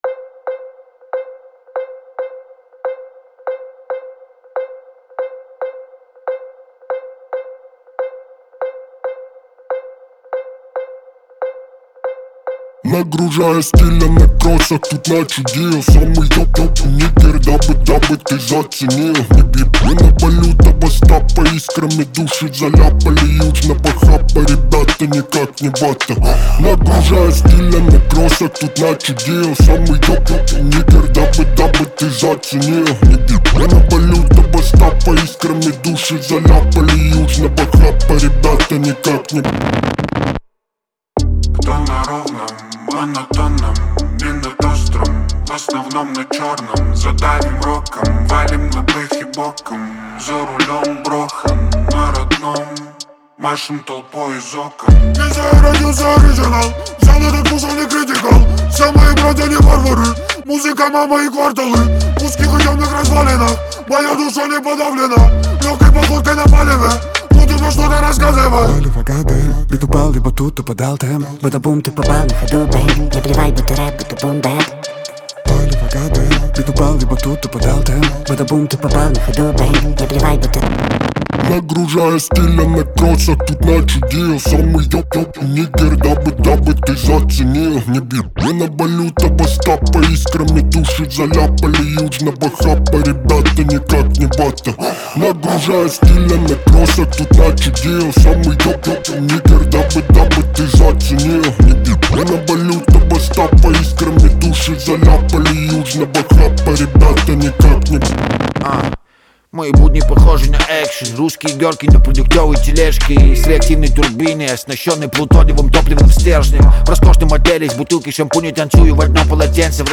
Трек размещён в разделе Русские песни / Эстрада.